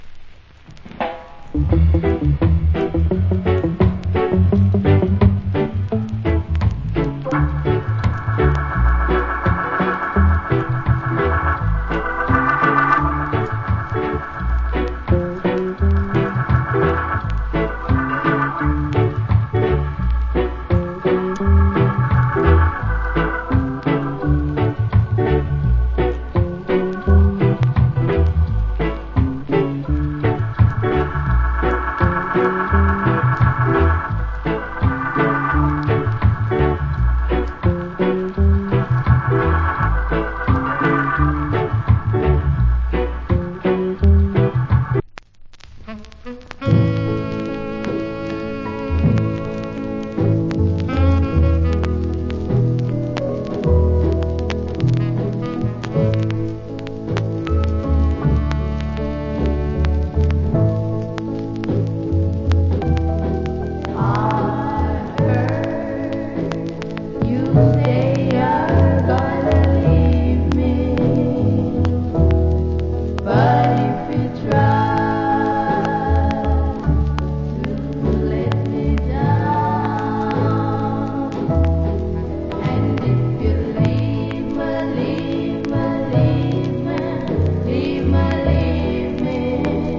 Rock Steady Inst.